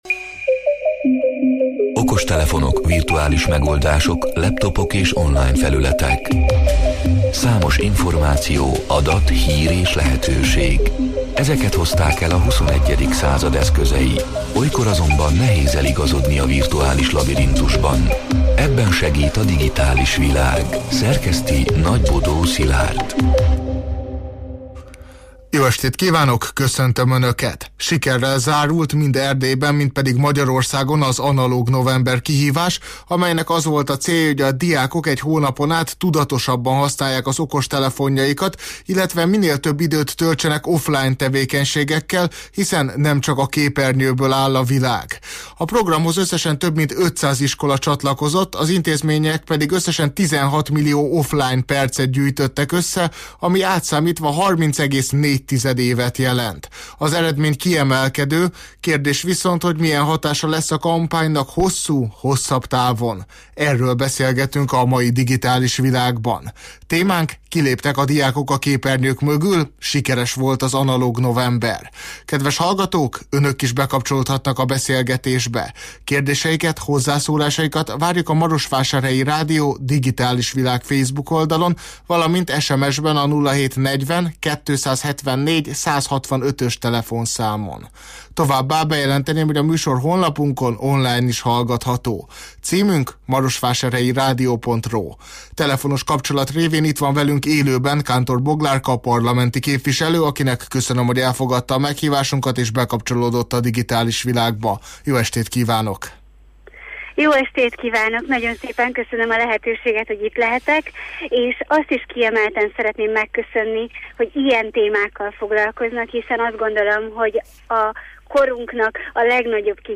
A Marosvásárhelyi Rádió Digitális Világ (elhangzott: 2025. december 16-án, kedden este nyolc órától élőben) c. műsorának hanganyaga: